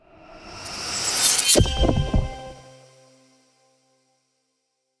divine-smite-caster-01.ogg